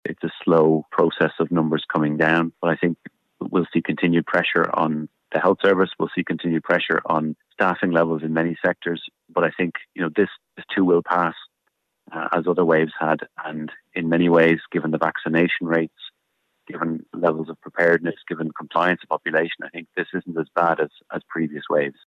Infectious Disease Consultant